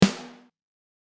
there are two versions, on is dry and the other one is with a room sample blended in for extra KSSSCHHHH.
It's a Sensitone Elite Custom Alloy snare with a powerstroke head btw.